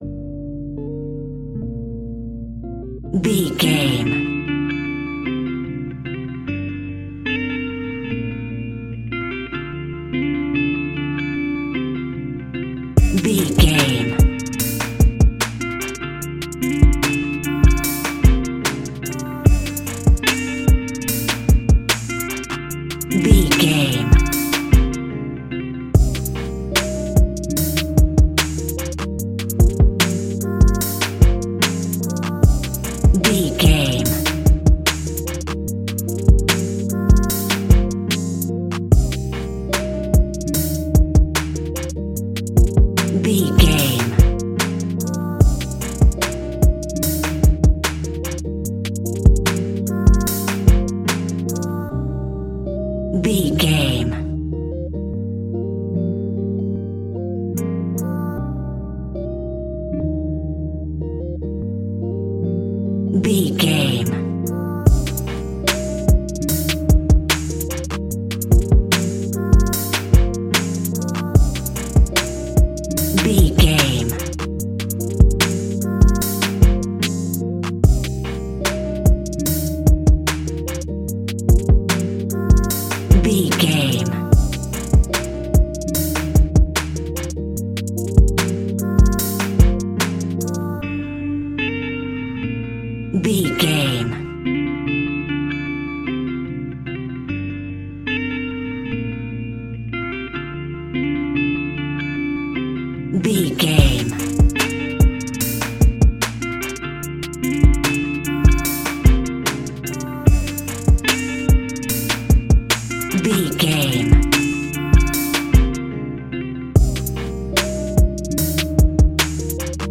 Aeolian/Minor
B♭
dreamy
smooth
mellow
acoustic guitar
drum machine
synthesiser